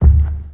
steps